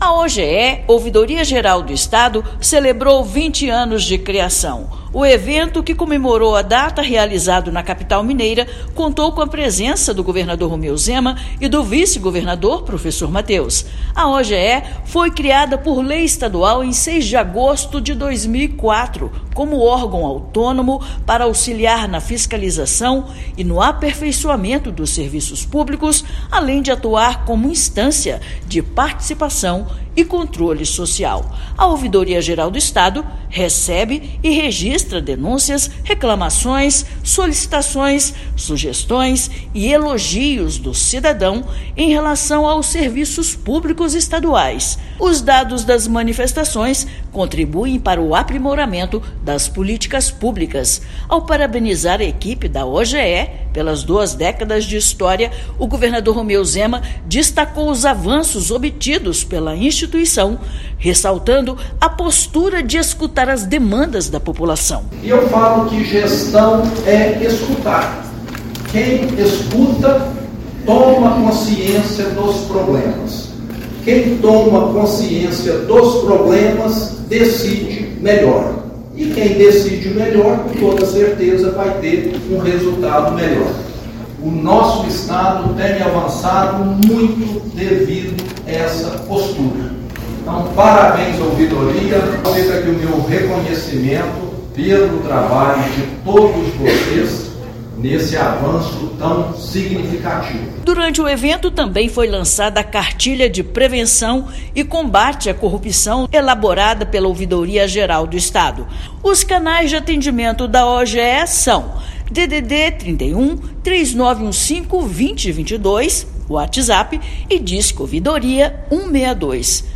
Evento comemorativo aconteceu nesta terça-feira (6/8), no auditório da Cemig, em Belo Horizonte. Ouça matéria de rádio.